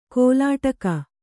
♪ kōlāṭaka